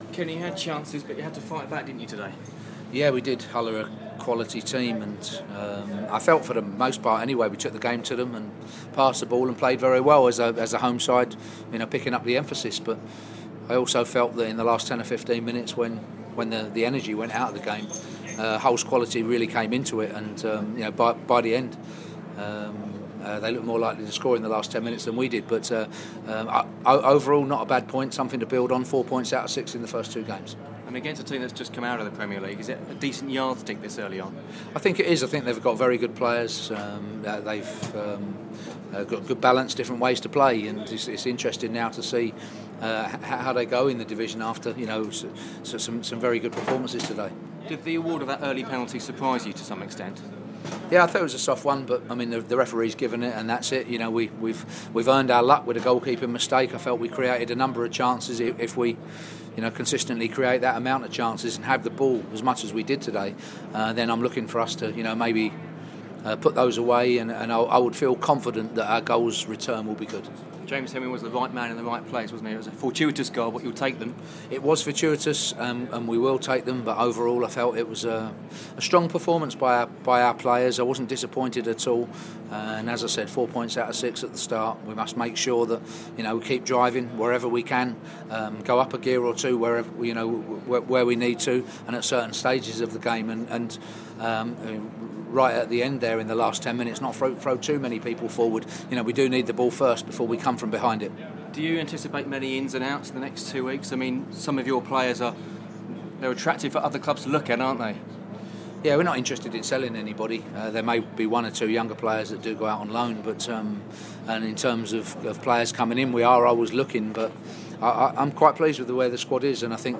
Kenny Jackett radio interview after Hull